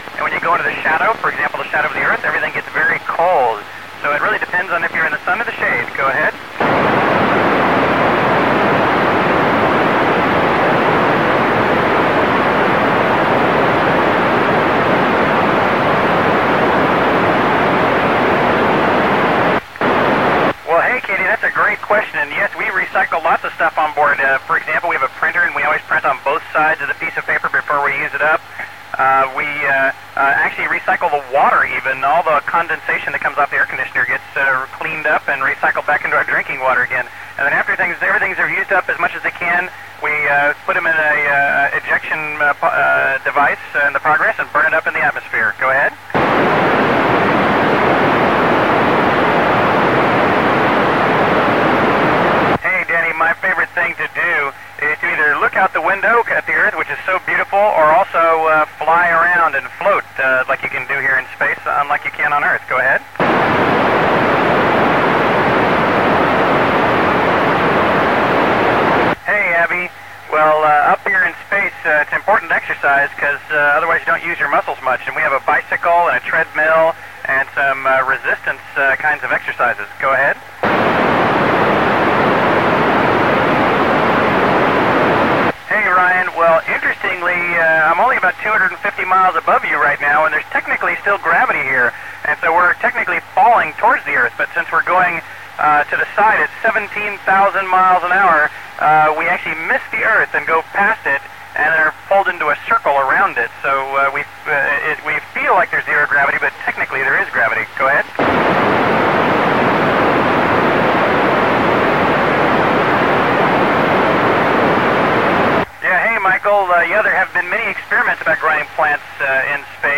About 17 years ago I recorded Richard Garriott's side of a conversation with a school in Warwick in England. The school was several hundred miles south, so well out of radio range, but obviously it's a clear path the thousand miles or so to the ISS!